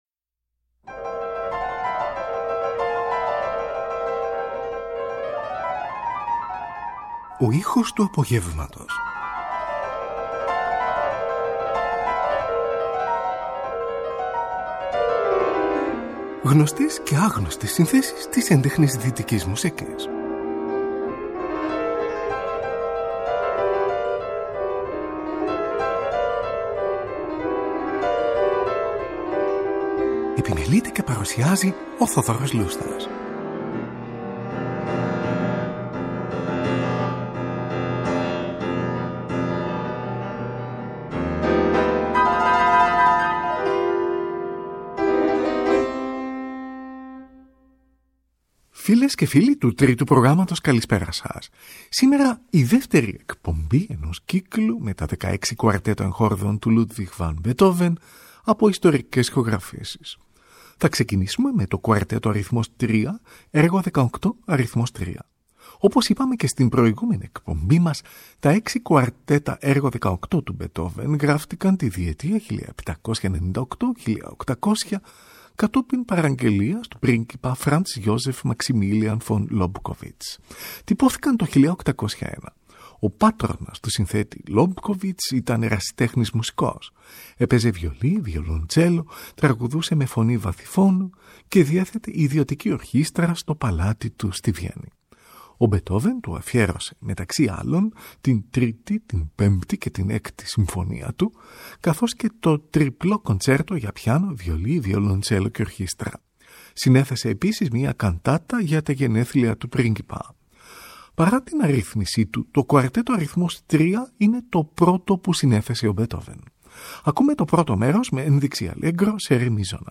Στη σημερινή εκπομπή ακούγονται τα εξής έργα Μουσικής Δωματίου του Ludwig van Beethoven: